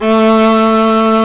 Index of /90_sSampleCDs/Roland - String Master Series/STR_Viola Solo/STR_Vla1 _ marc